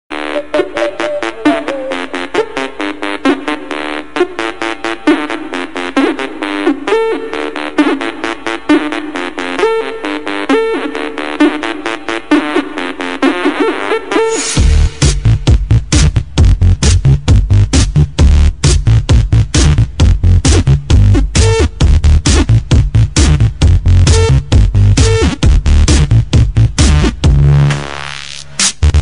Dance